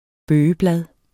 Udtale [ ˈbøːjə- ]